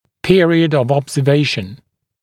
[‘pɪərɪəd əv ˌɔbzə’veɪʃn][‘пиэриэд ов ˌобзэ’вэйшн]период наблюдения